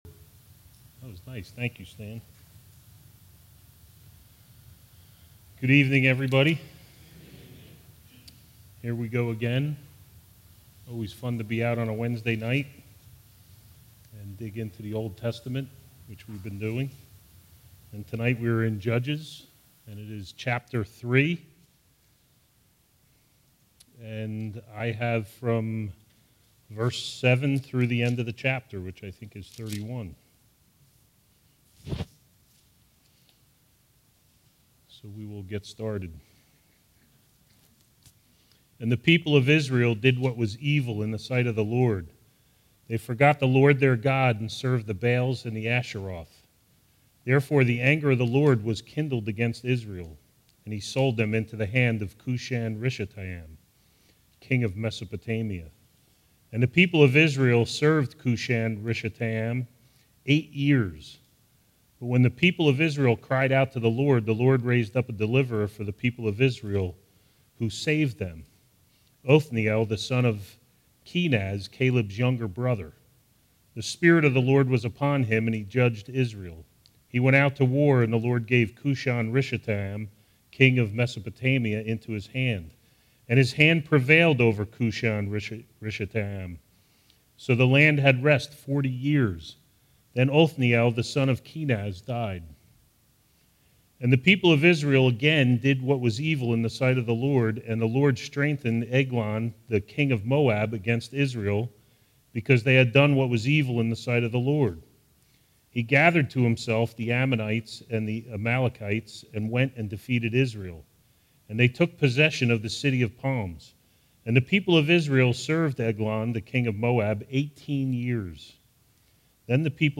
All Sermons Judges 3:7-31